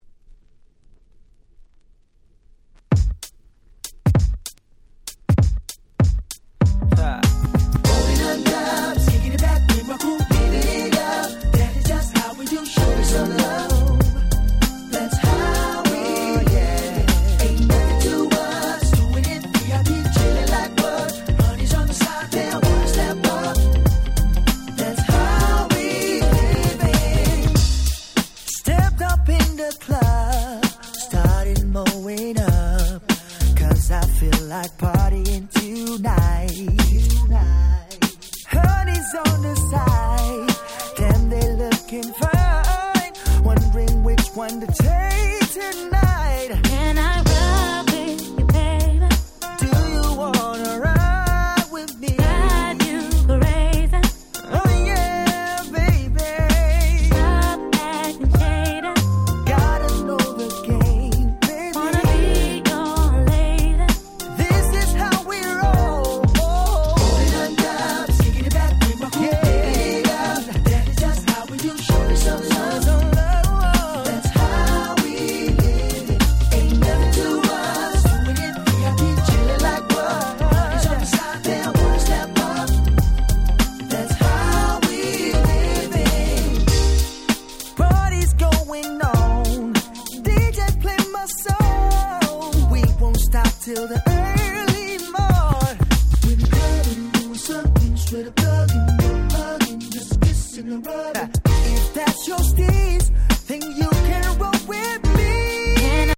04' Super Nice R&B♪
USメインストリームな感じもしっかり踏襲しつつも洗練されたUK R&Bっぽさも兼ね備えた超使える1曲です！